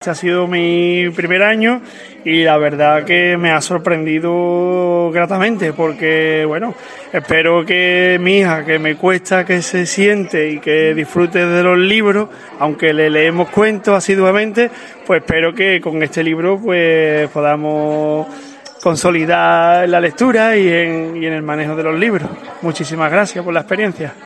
Imágenes de dos de los cuentos adaptados elaborados por los padres y madres del tallerFinalmente, un padre, que participó en el taller muy activamente con sus compañeras,